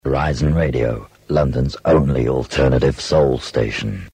Station Jingle Package